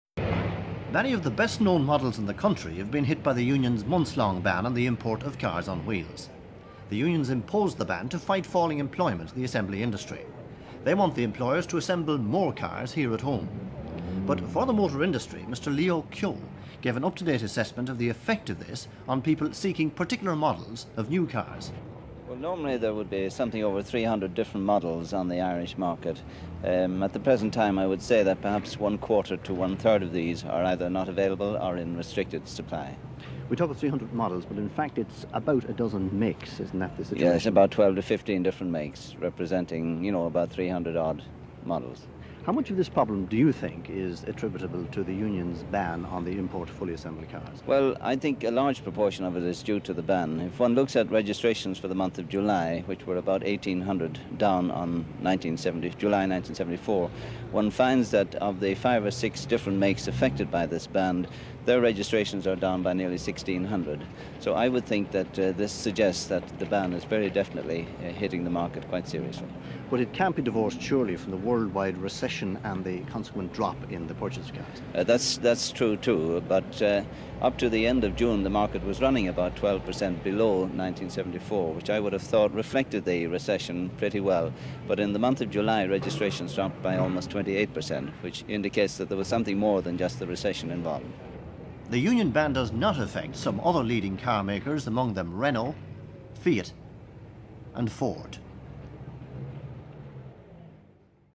(Broadcast on 26 August 1975.)